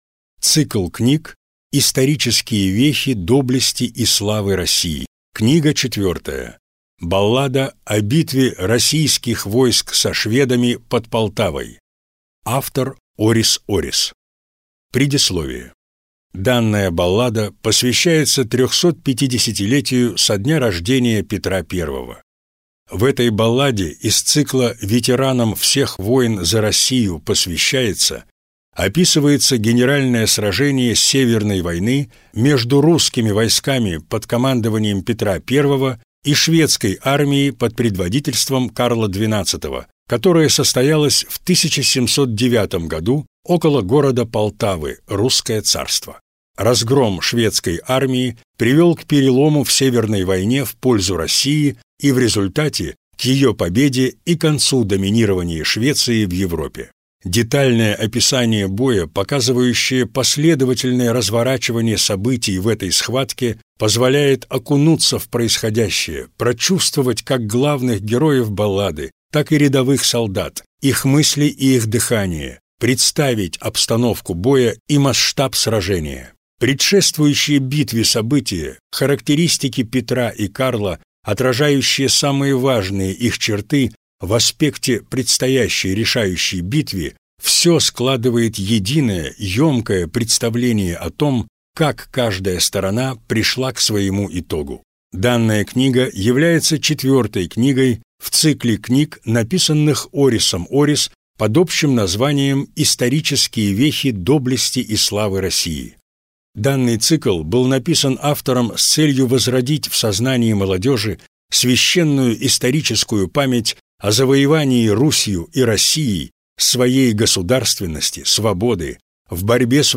Аудиокнига Баллада о битве российских войск со шведами под Полтавой | Библиотека аудиокниг